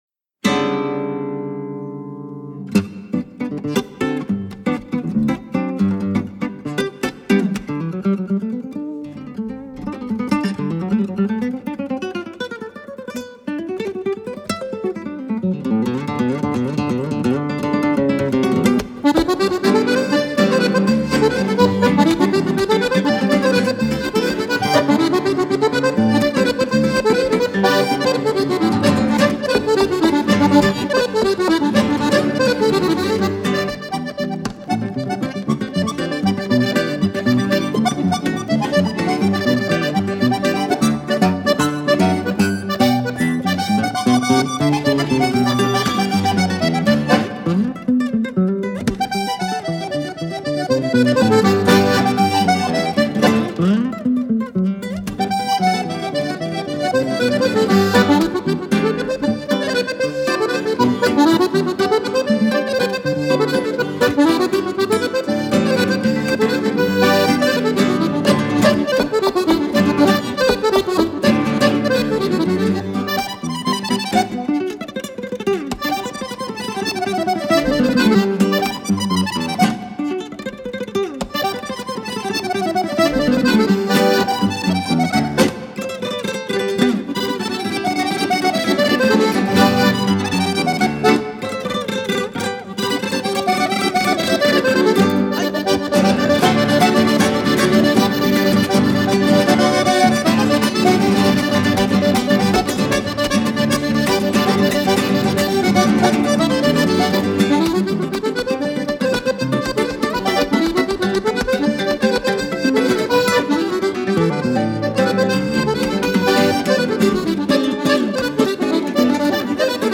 Tags: Violão e Banda
Gaita Ponto
Violão 7 Cordas
Contrabaixo